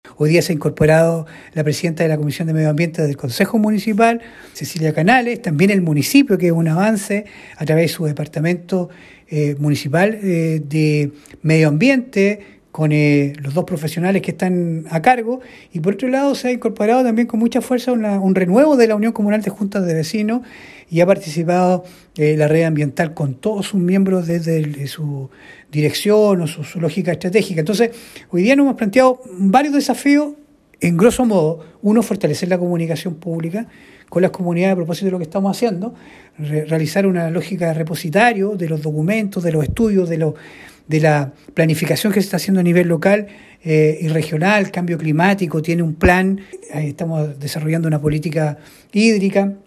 El Consejero Regional de la Provincia de Osorno y Presidente de la Comisión de Medioambiente del Consejo Regional de Los Lagos, Francisco Reyes indicó que estas reuniones se retoman luego de haber conseguido el objetivo principal que es la norma secundaria para los ríos de Osorno, además de indicar que se deben ir generando otros lineamientos para avanzar en conjunto.